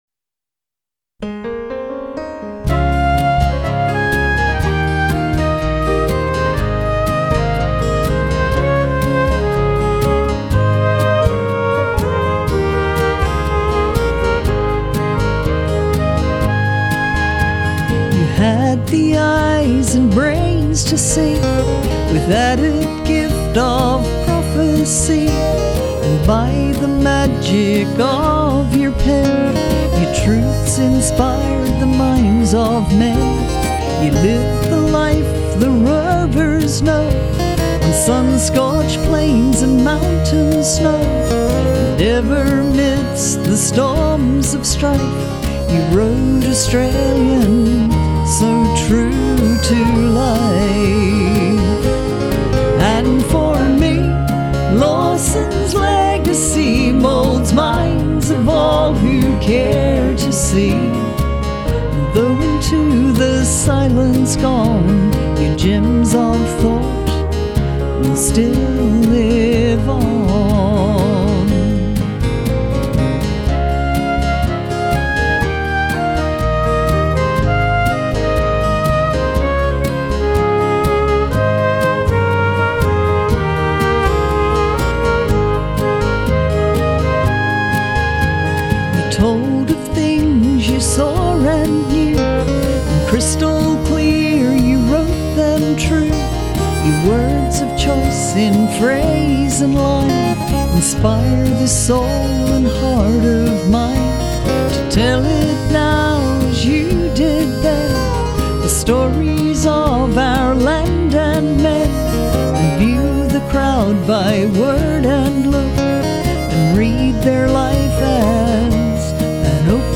traditional bush ballad